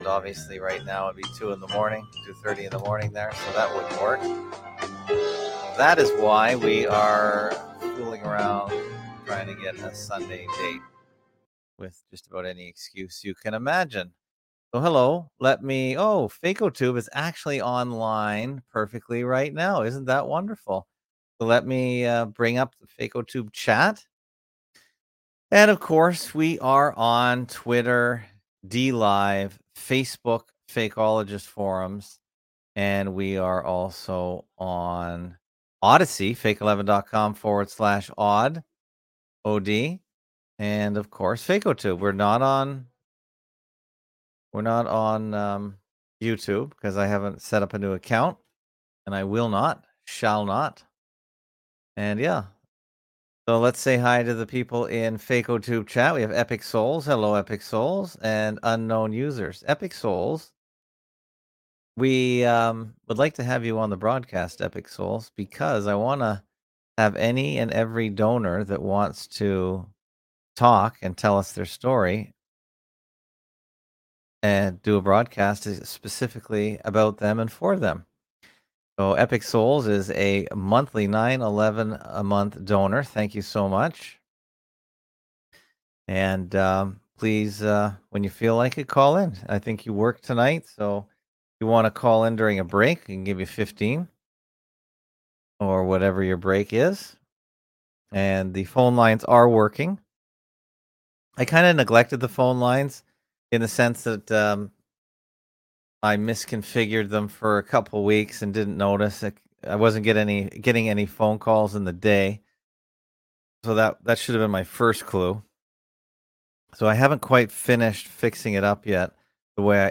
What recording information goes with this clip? Category: Live Stream